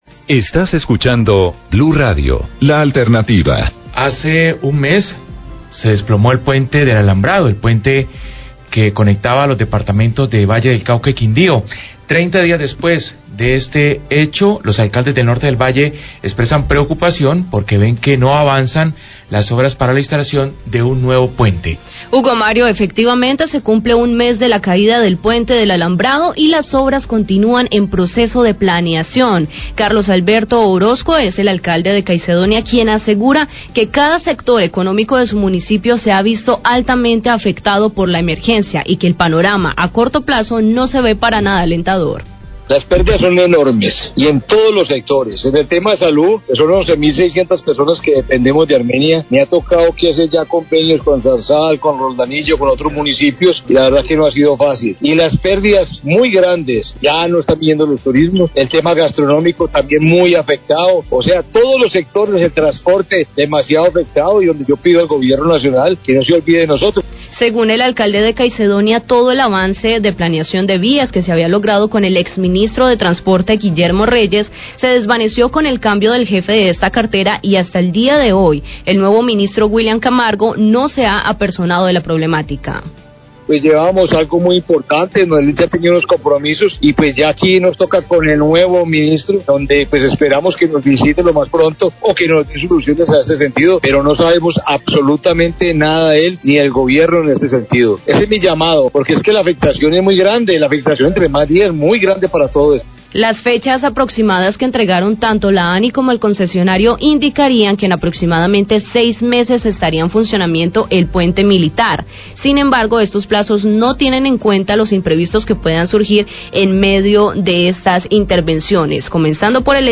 Alcalde Caicedonia habla de pérdidas económicas a un mes de caída pte del Alambrado
Radio
Carlos Alberto Orozco, alcalde de Caicedonia, habla de las pérdidas económicas para los comerciantes de la región y para los  usuarios de salud. Hace un llamado al nuevo Ministro de Transporte que se apersone de la problemática.